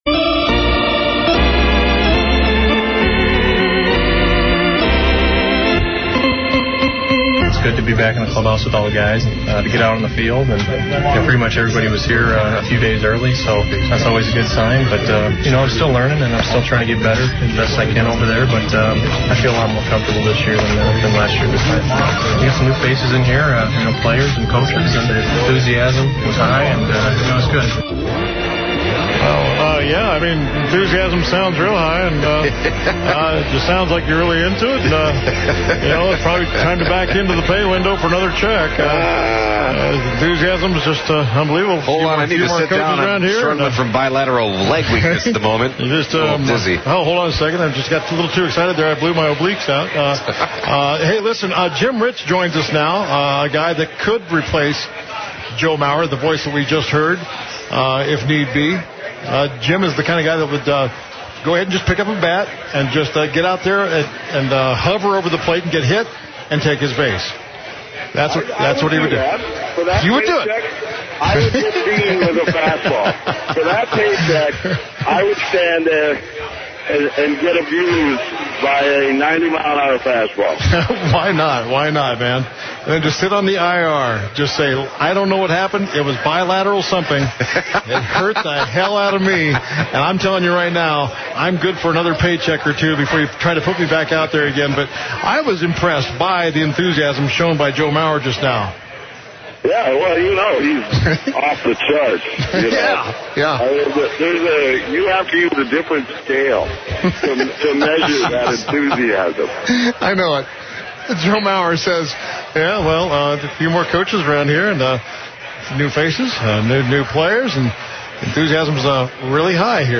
live from Spring Training in Fort Meyers, Florida
stays on the phone